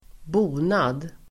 Ladda ner uttalet
Uttal: [²b'o:nad]